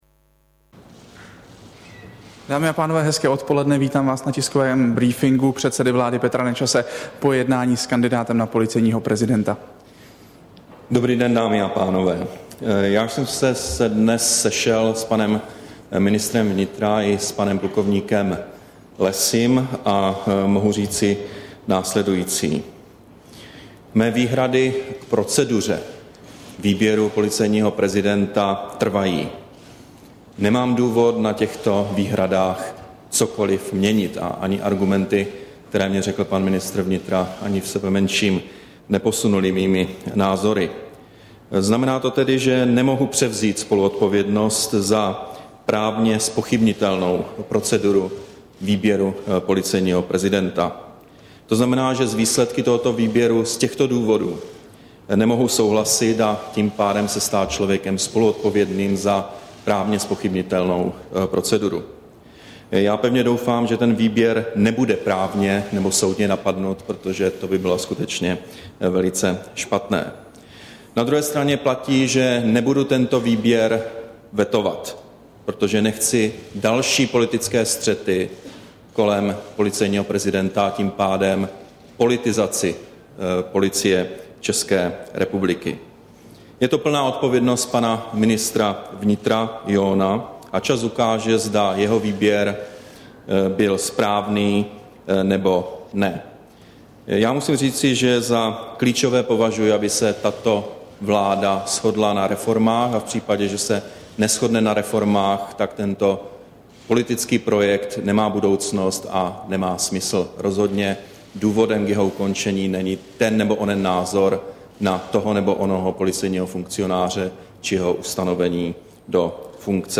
Tiskový brífink předsedy vlády Petra Nečase po schůzce s plk. Petrem Lessym, 24. ledna 2011